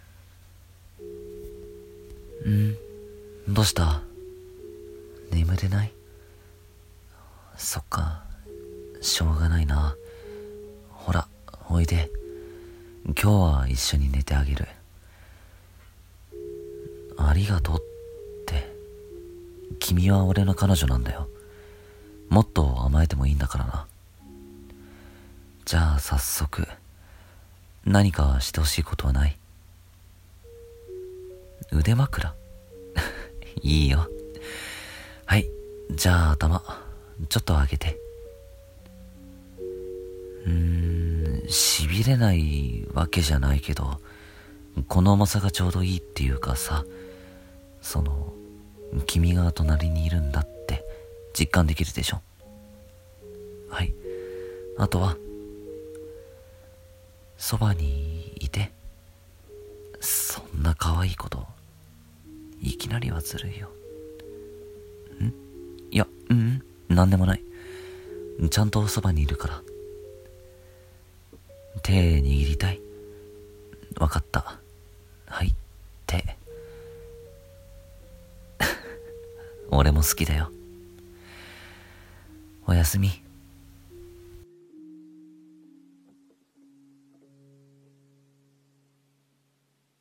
【声劇】いつも頑張ってる君へ